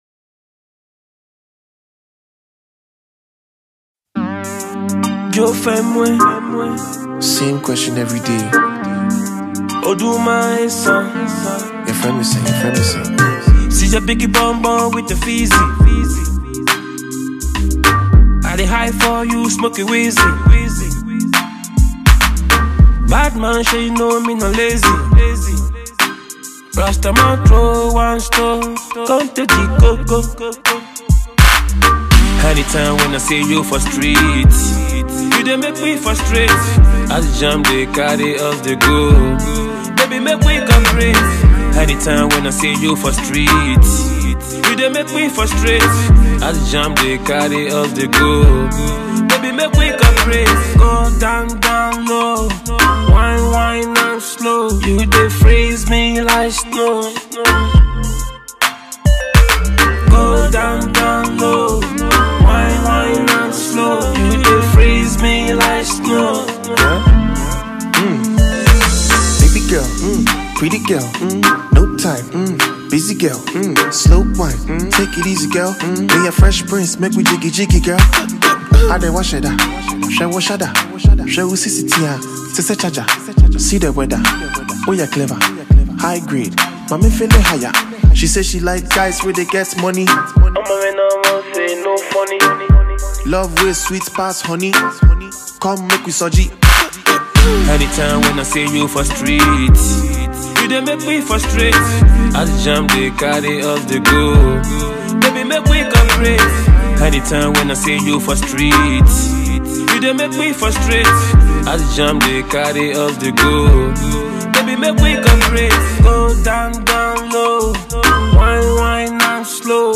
Ghana Music Listen
the talented Ghanaian singer and rapper